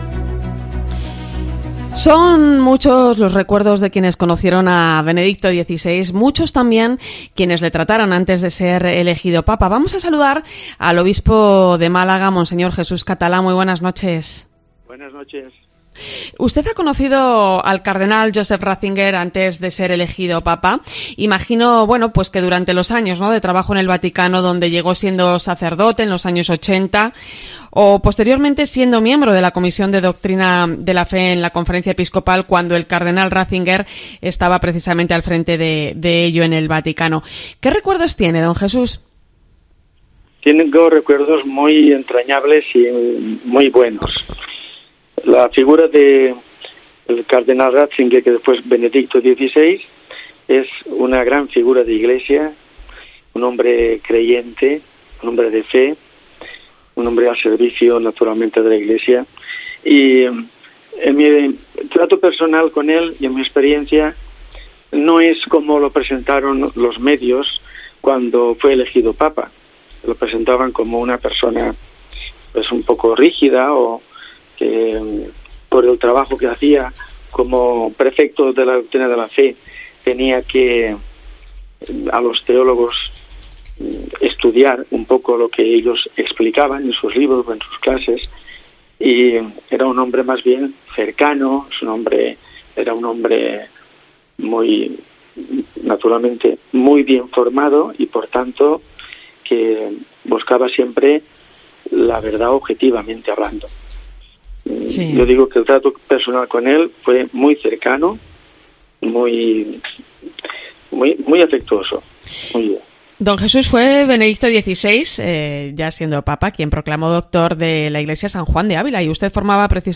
AUDIO: El obispo de Málaga, que conoció al cardenal Joseph Ratzinger antes de ser elegido Papa, ha estado en 'La Linterna de la Iglesia'